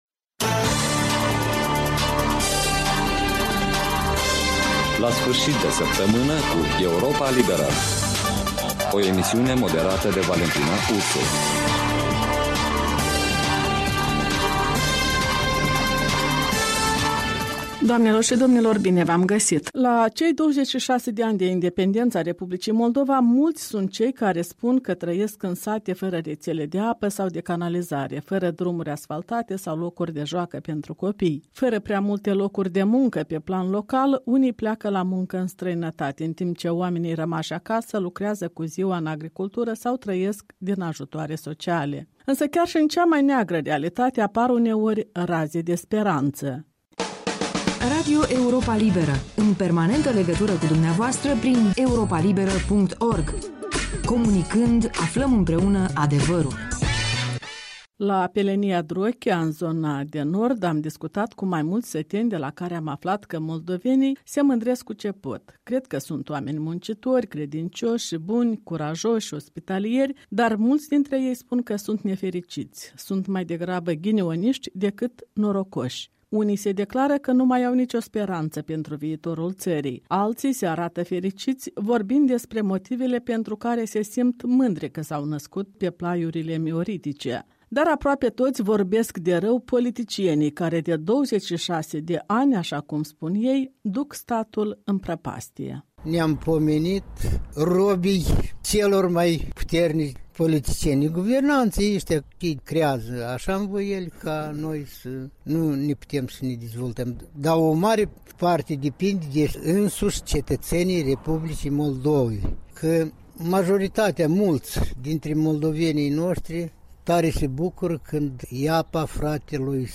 Dialog de ziua independenței